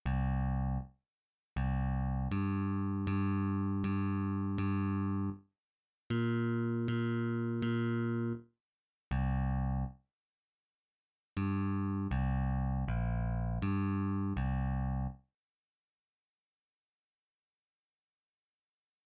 Exercise 2: Counting in 4/4 Time
This is a very simple counting exercise using quarter notes.
Ex-1-Counting-Quarters.mp3